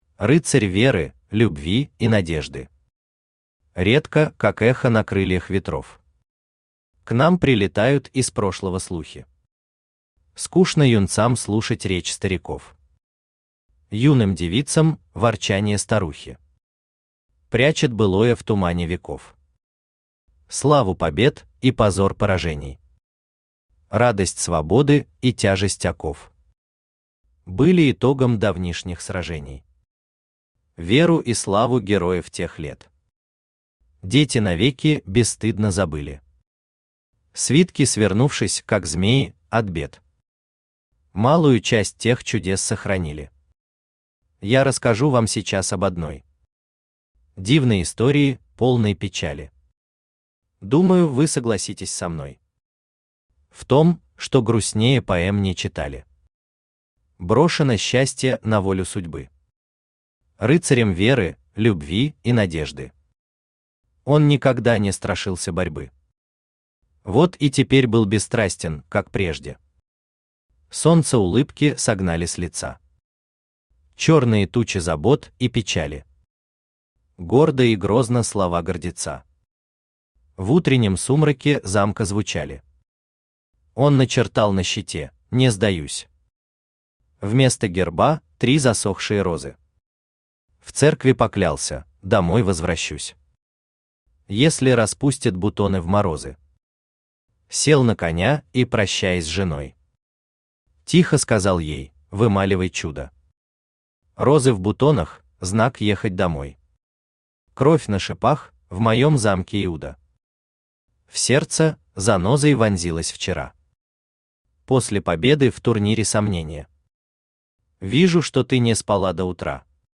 Аудиокнига Рыцарь веры, любви и надежды | Библиотека аудиокниг
Aудиокнига Рыцарь веры, любви и надежды Автор Николай Николаевич Самойлов Читает аудиокнигу Авточтец ЛитРес.